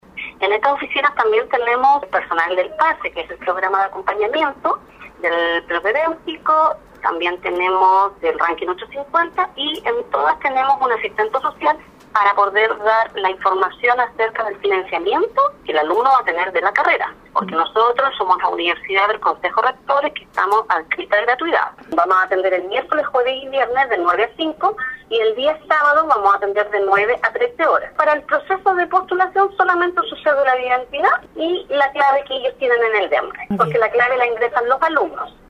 En conversación con Radio Sago